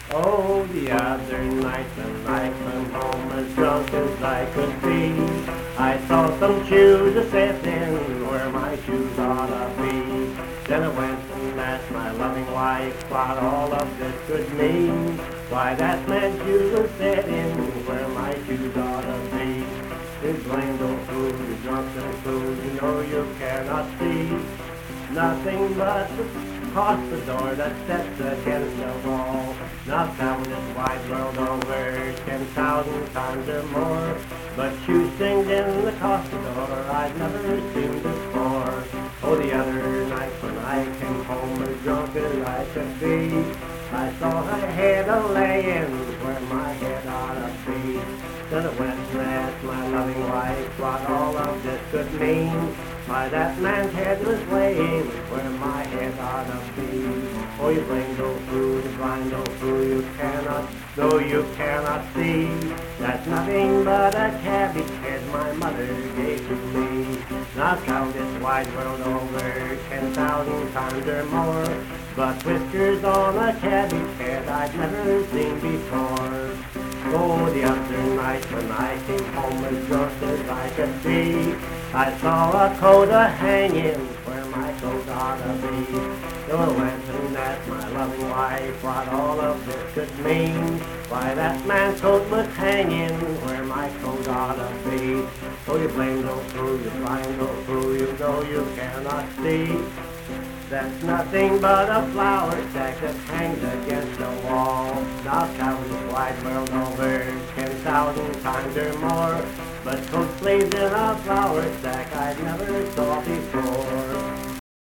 Accompanied vocal and guitar music
Performed in Hundred, Wetzel County, WV.
Guitar, Voice (sung)